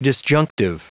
Prononciation du mot disjunctive en anglais (fichier audio)
Prononciation du mot : disjunctive